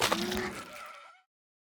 1.21.5 / assets / minecraft / sounds / block / sculk_sensor / break4.ogg